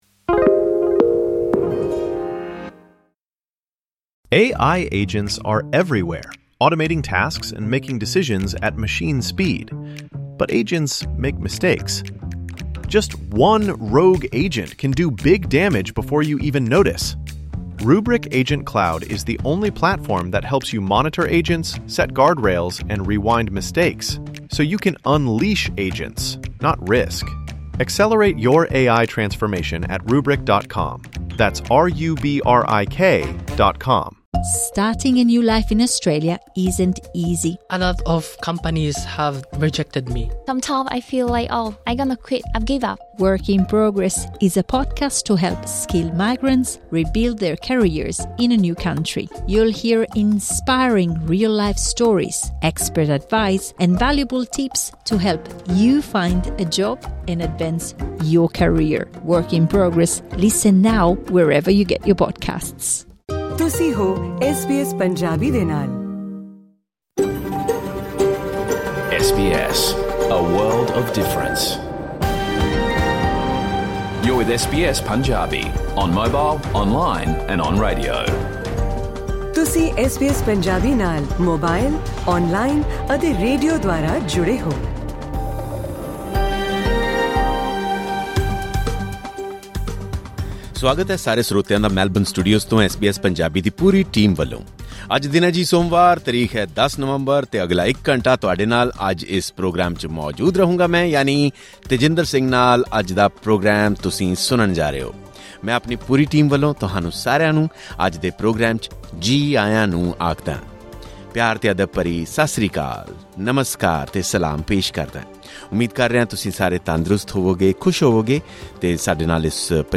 Listen to the full radio program of SBS Punjabi